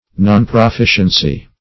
Search Result for " nonproficiency" : The Collaborative International Dictionary of English v.0.48: Nonproficiency \Non`pro*fi"cien*cy\, n. Want of proficiency; failure to make progress.